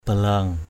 /ba-la:ŋ/